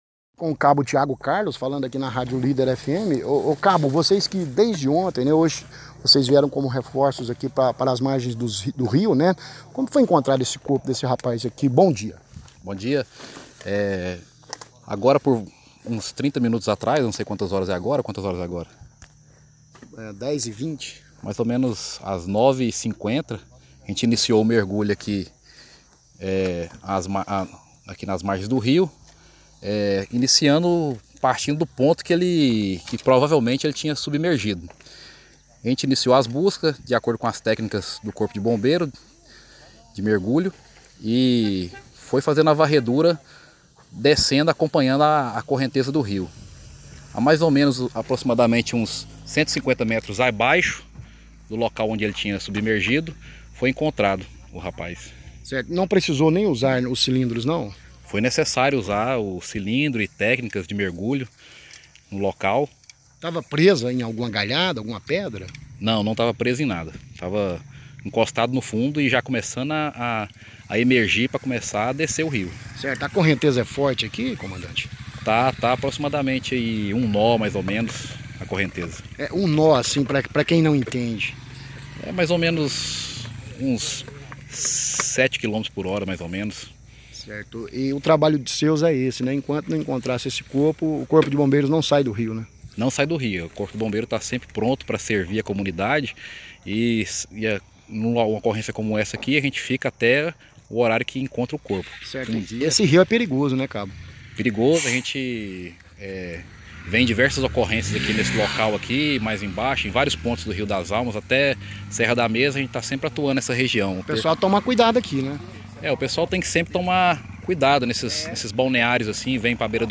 Ouça a entrevista do oficial do Corpo de Bombeiros